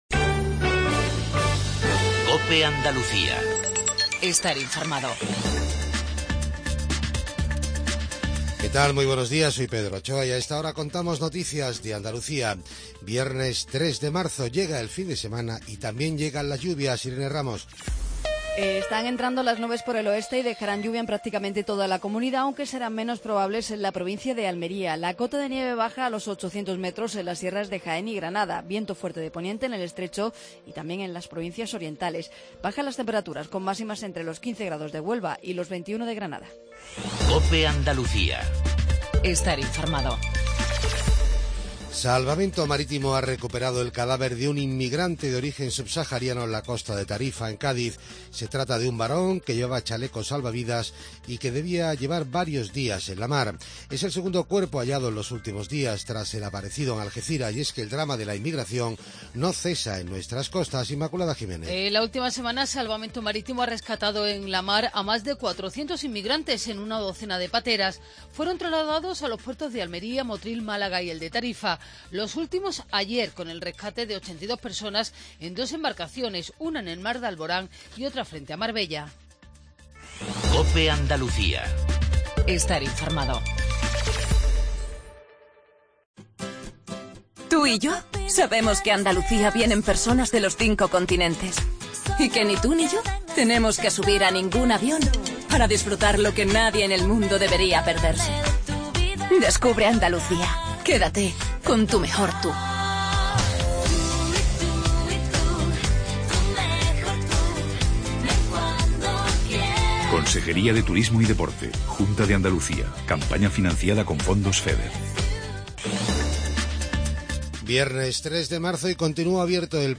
INFORMATIVO REGIONAL MATINAL 7:20